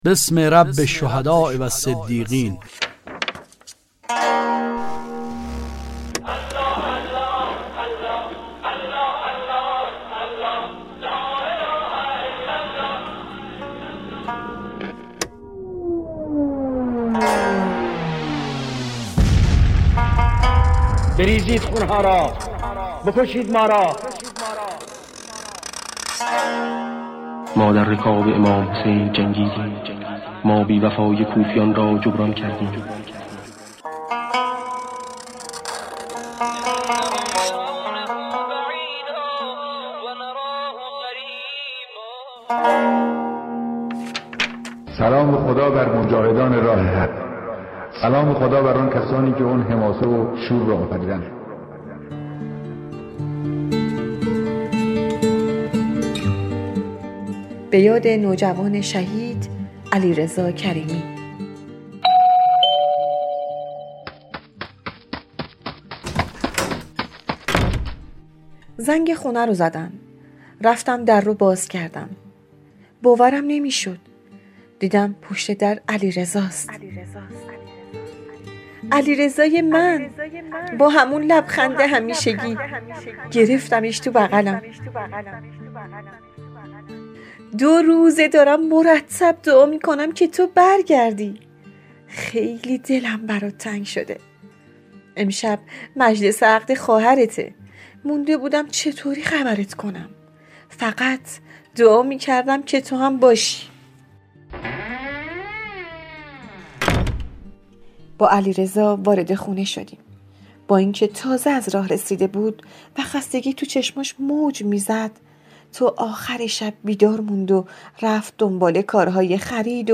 صداپیشگان :